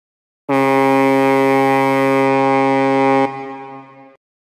Foghorn.mp3